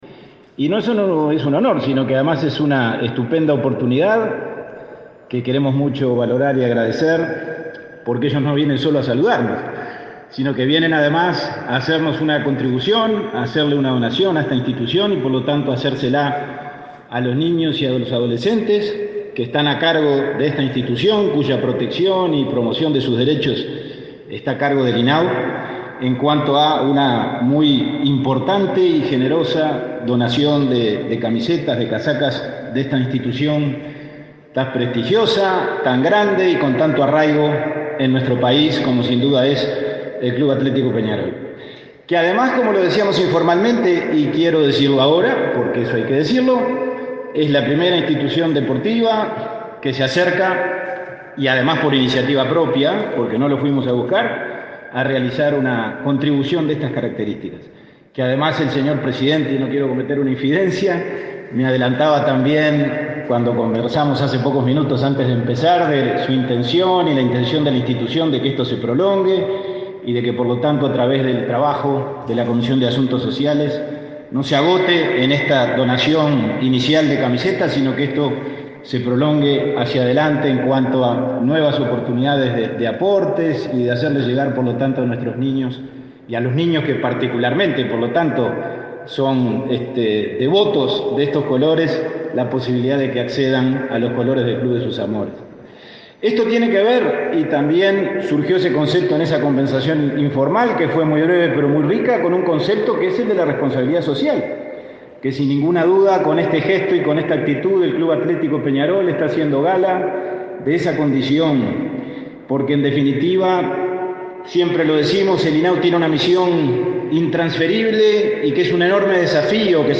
Palabras del presidente del INAU, Pablo Abdala